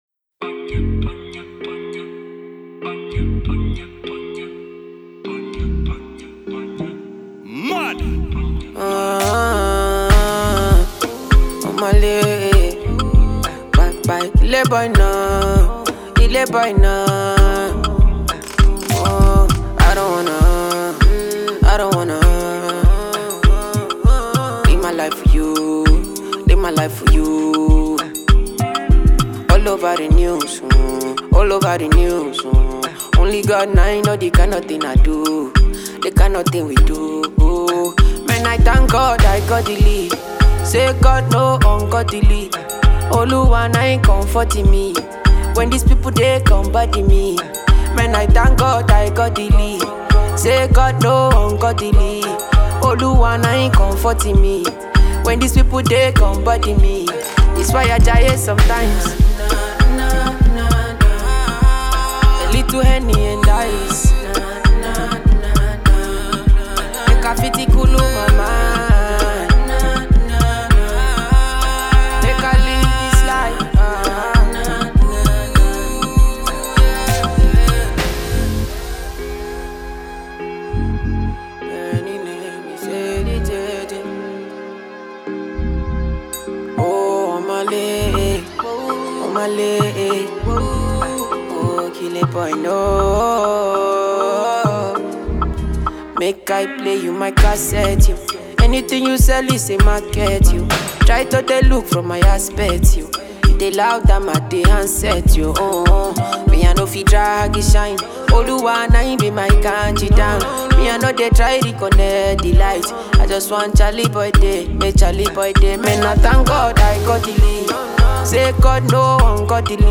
Nigerian Afro Fusion singer and rave of the moment